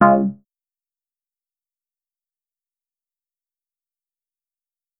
modalert.wav